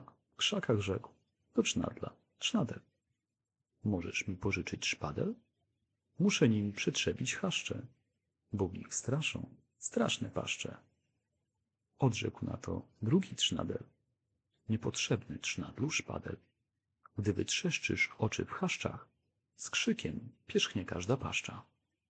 Nagrania lektorskie